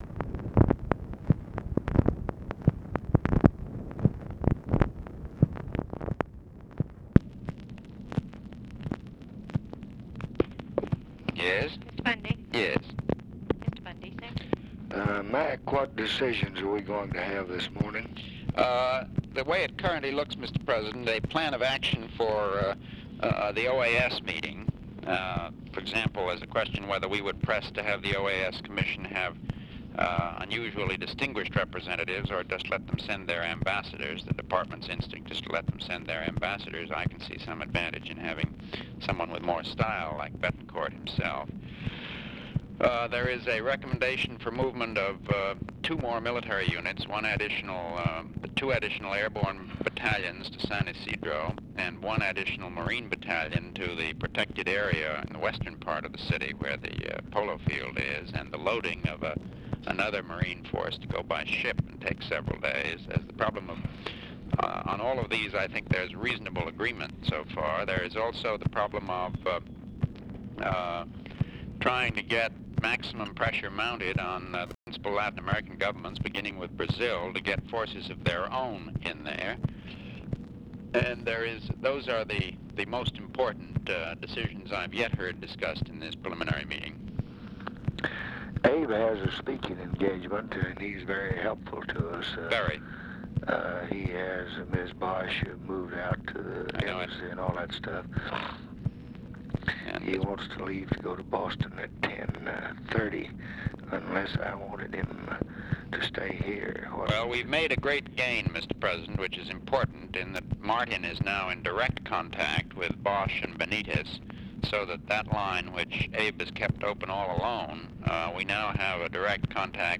Conversation with MCGEORGE BUNDY, May 1, 1965
Secret White House Tapes